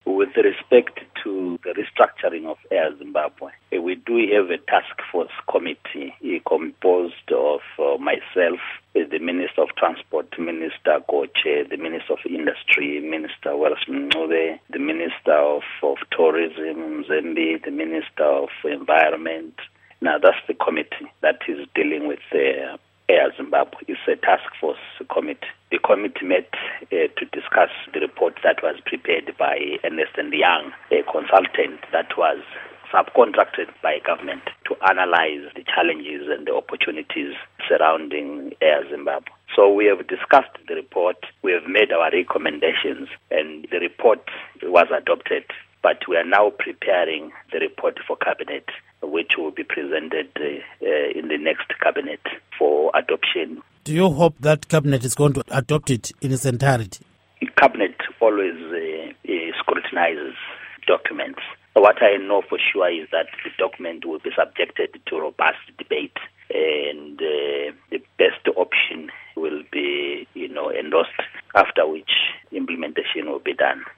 Interview With Gorden Moyo